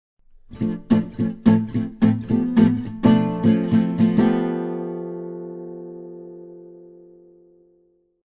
This chordal sequence (min, min/maj7, min7, min6) is commonly known as a minor “line cliche.” This progression is usually voiced so that there is a descending chromatic line starting from the tonic moving down to the minor 6 (i.e. in D minor the line goes D, C#, C, B).
This example of a line cliche places the chromatic line in the top voice.
In the audio example I play the line cliche twice: First time as written and then the second time I play a common rhythmic variation.